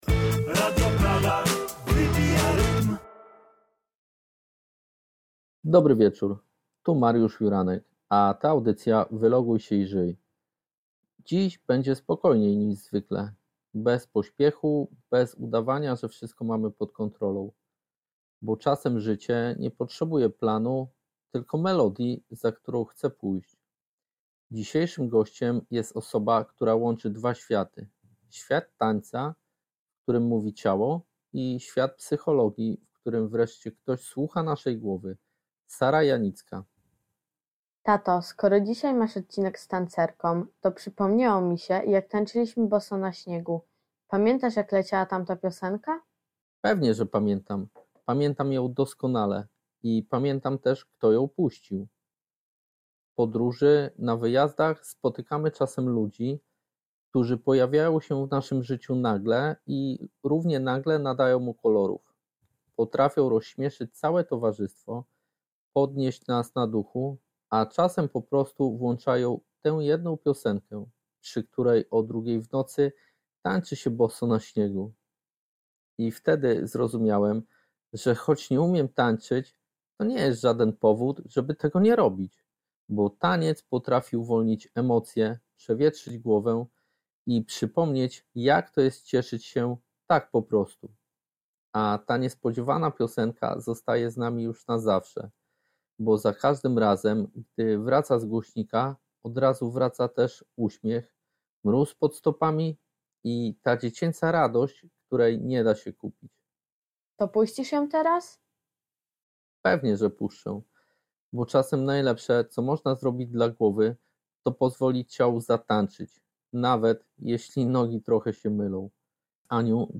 To rozmowa o tym, jak odzyskać kontakt z ciałem i głową, kiedy świat pędzi za szybko.
Ciepło, mądrze i z dużą szczerością.